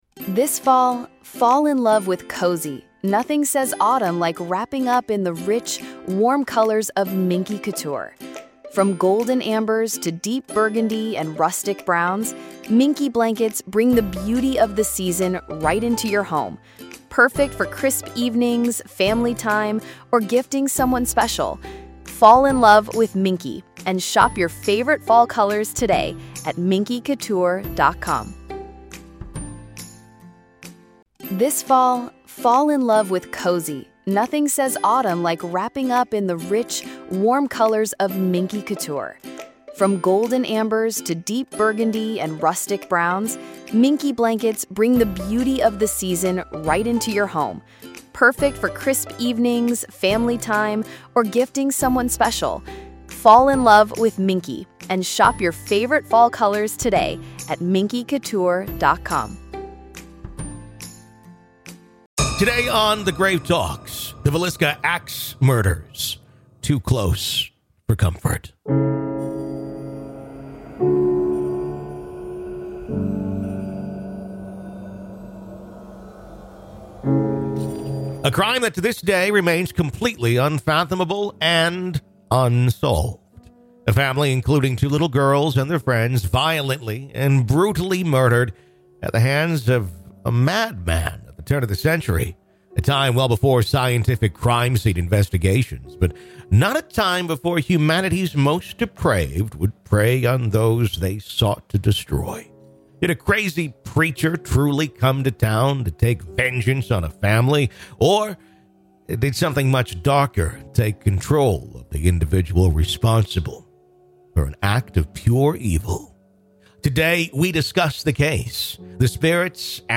In this chilling conversation